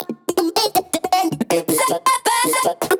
这些循环专为地下电子音乐打造，容量为 145 MB，包含 24 位 WAV 格式、160 BPM 的音频文件，可直接导入你的项目。
• 160 BPM